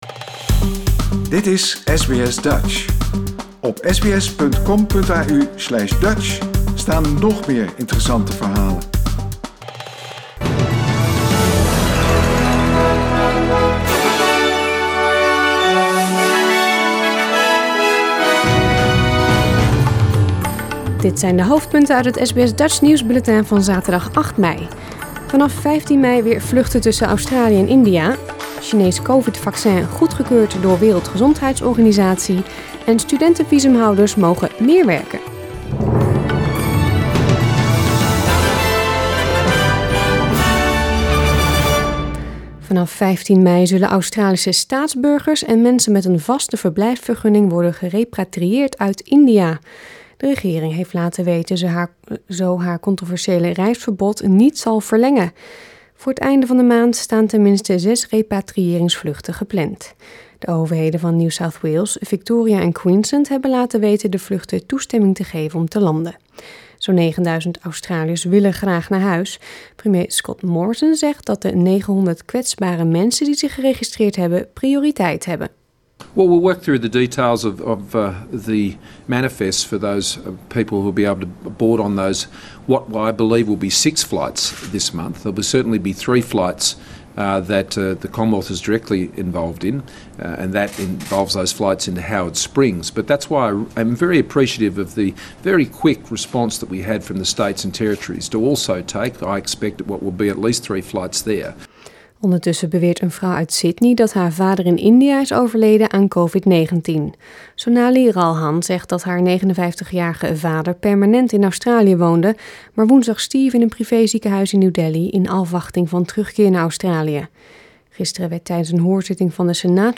Nederlands/Australisch SBS Dutch nieuwsbulletin van zaterdag 8 mei 2021